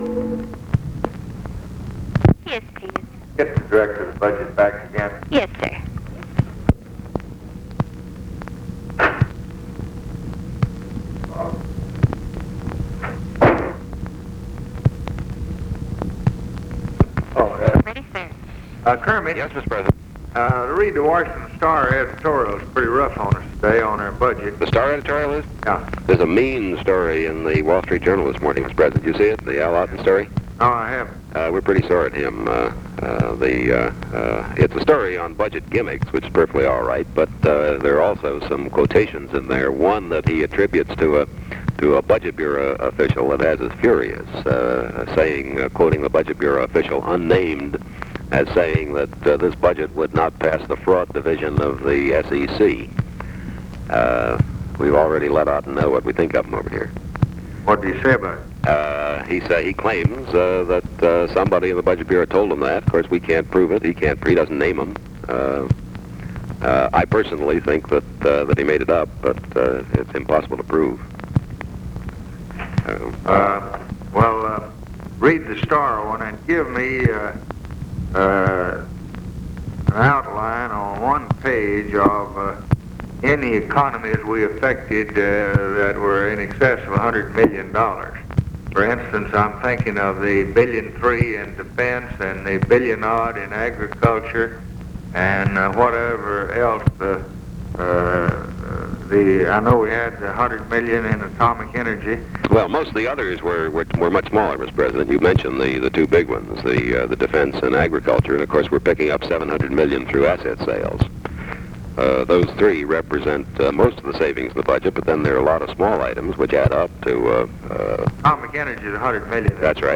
Conversation with KERMIT GORDON, January 22, 1964
Secret White House Tapes